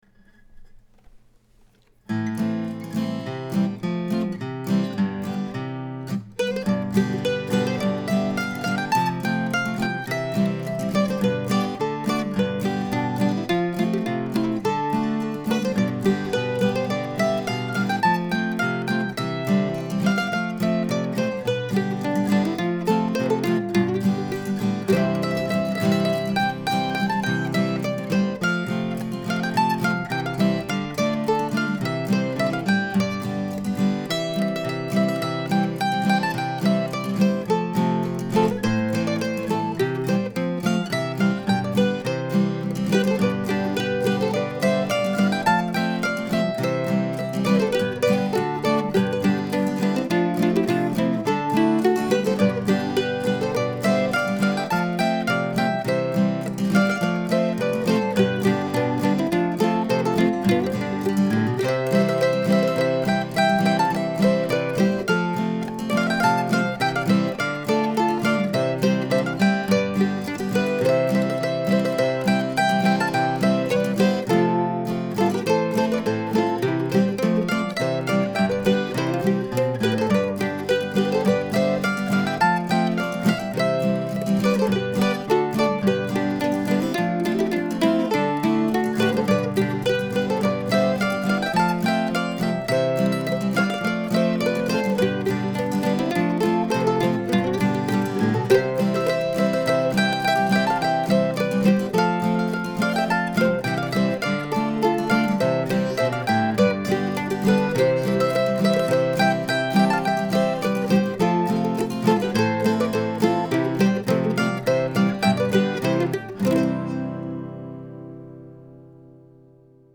I finally got around to writing a harmony part and recording the tune yesterday.